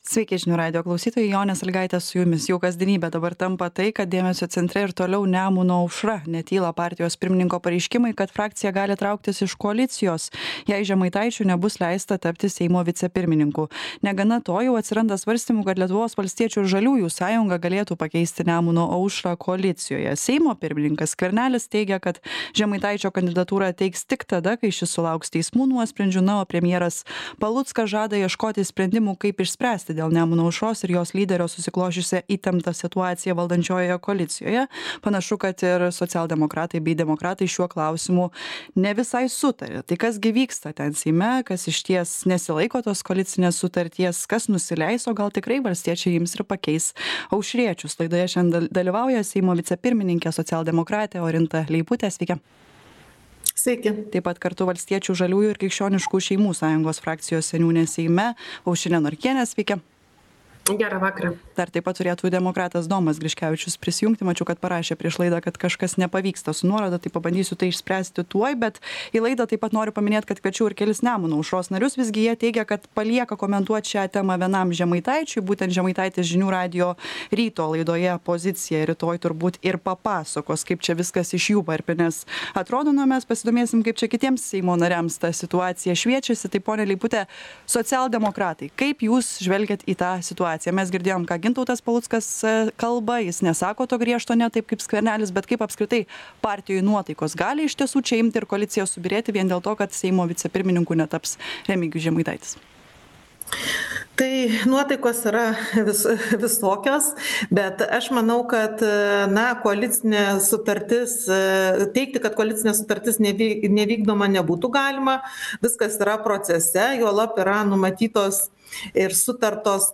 Aktualusis interviu Žiūrėkite Atsisiųsti Atsisiųsti Ar tikrai gali griūti koalicija? 2024-12-17
Laidoje dalyvauja Seimo vicepirmininkė, socialdemokratė Orinta Leiputė , demokratas Domas Griškevičius ir valstiečių, žaliųjų ir Krikščioniškų šeimų sąjungos frakcijos seniūnė Aušrinė Norkienė.